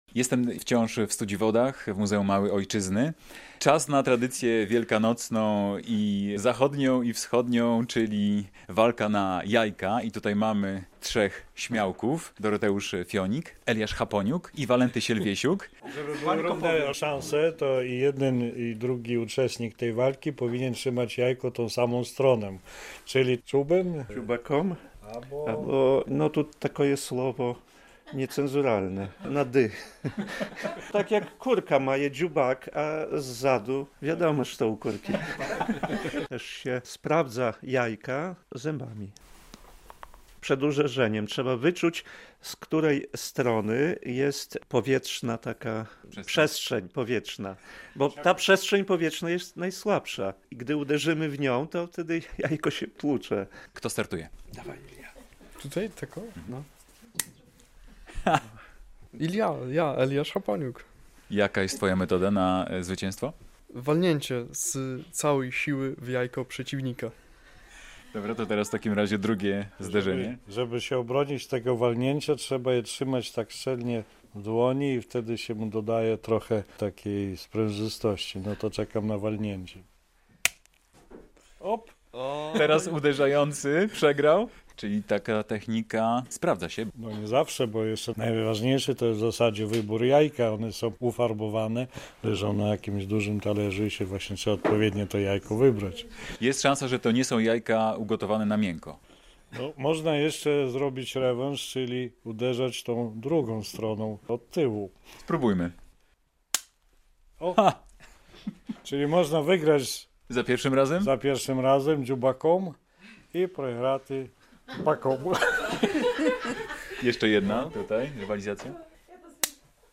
Walka na jajka w Muzeum Małej Ojczyzny w Studziwodach